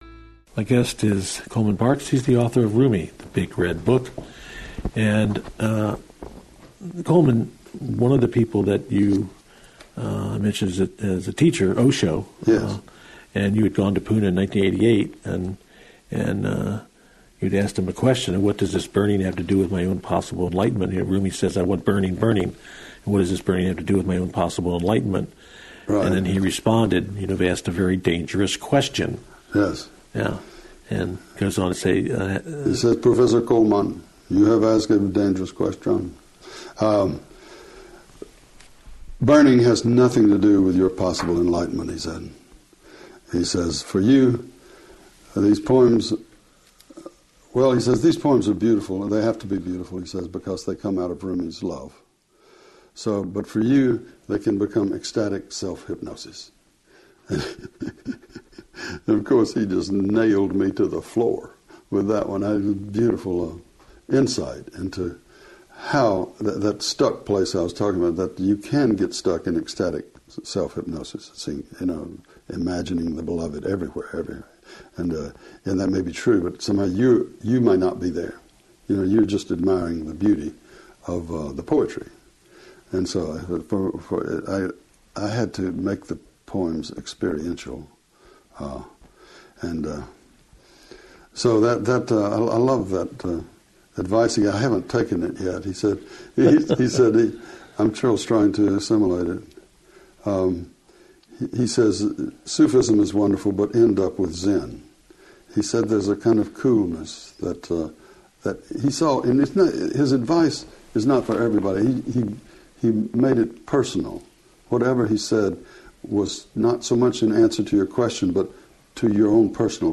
Coleman Barks on Osho (New Dimensions Interview)
02-Coleman-Barks-on-Osho-New-Dimensions-Interview.mp3